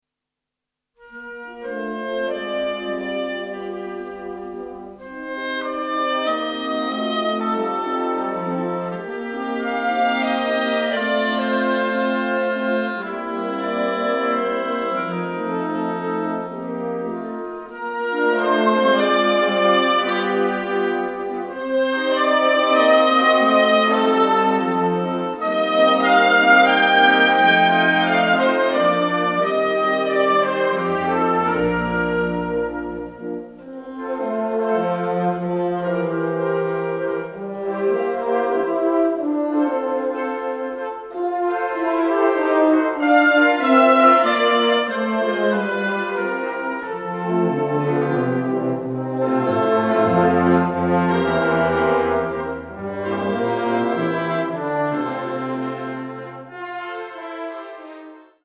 • Besetzung: Blasorchester